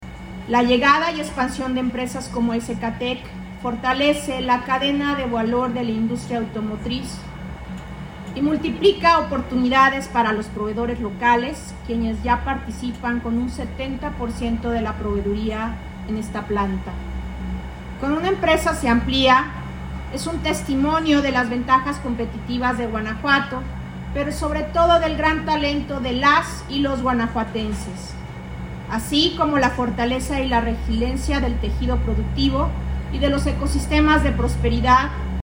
AudioBoletines
Cristina Villaseñor Aguilar, secretaria de economía